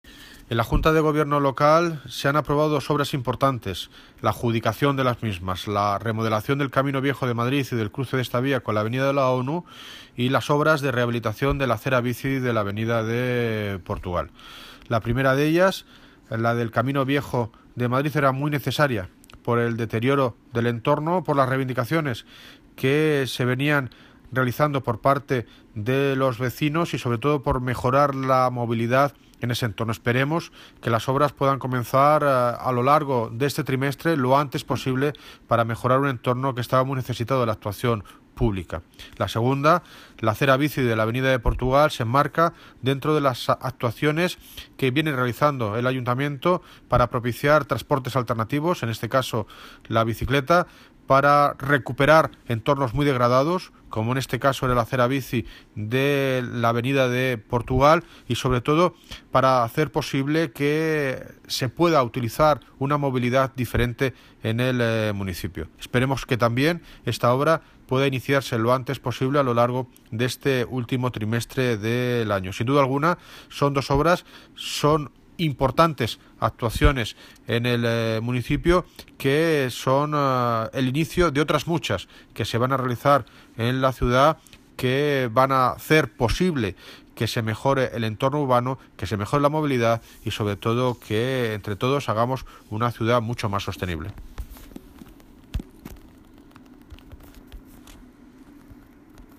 Audio - David Lucas (Alcalde de Móstoles) Sobre ADJUDICACION OBRAS